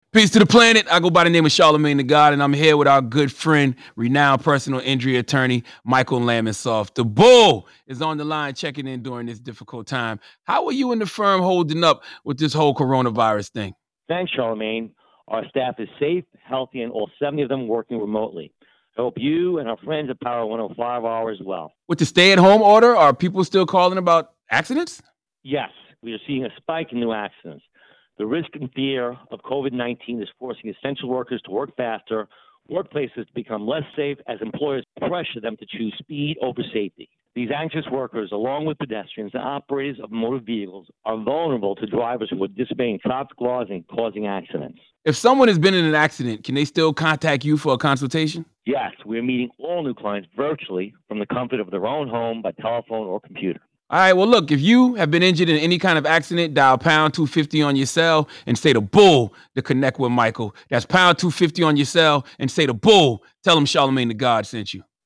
Radio Interviews
interview with Charlamagne Tha God